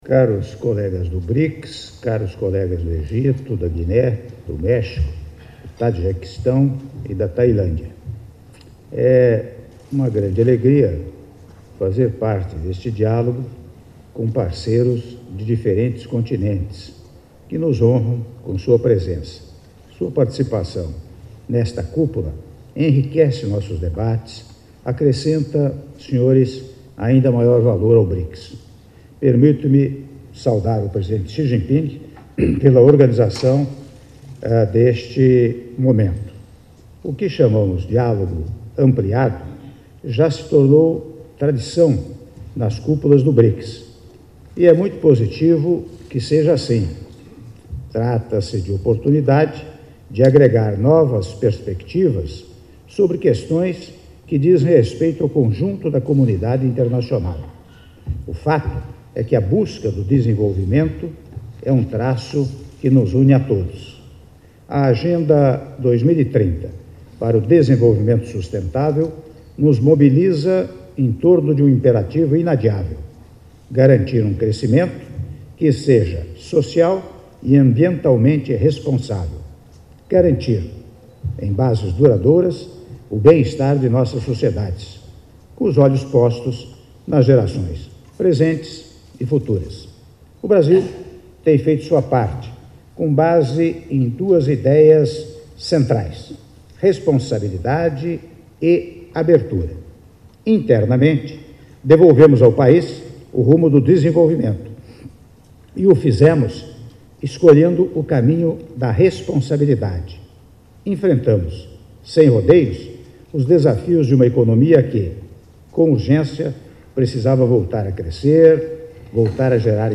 Áudio do discurso do Presidente da República, Michel Temer, durante Diálogo dos Chefes de Estado e de Governo do Brics e das Economias Emergentes e Países em Desenvolvimento - Xiamen/China (07min06s)